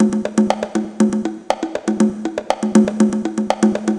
120_bongo_4.wav